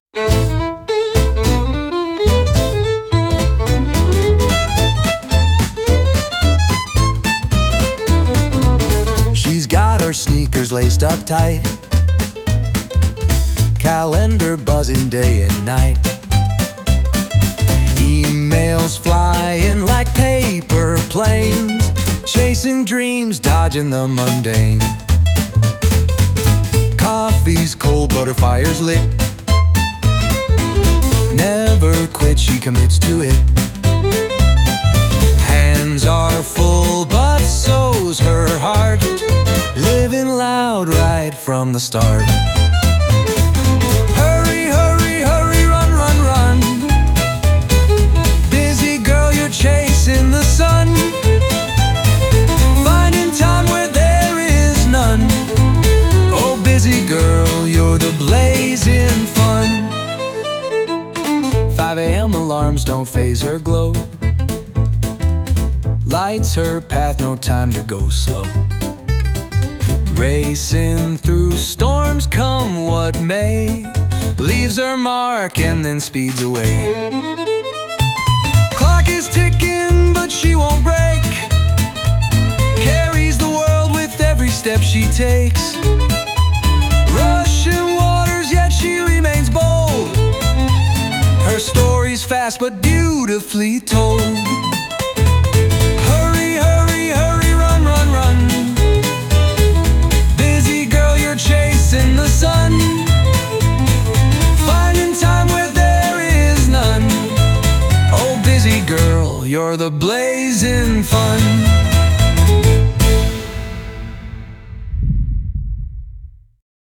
小気味よいバイオリンのリズムと、男性の歌声が楽しいジャズテイストの一曲。
リズムがはっきりしていて踊りやすく、元気いっぱいのジュニア選手にぴったり。